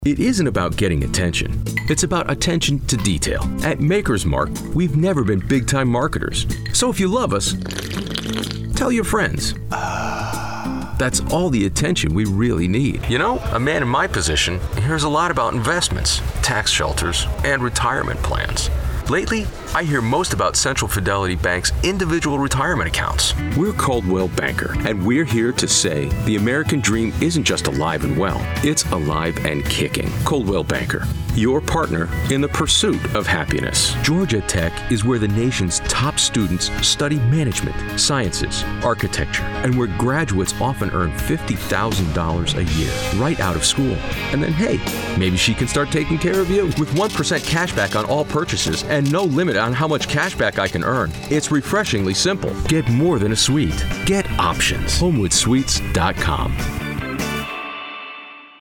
Warm, friendly, guy next door, conversational, commanding, character,
Sprechprobe: Werbung (Muttersprache):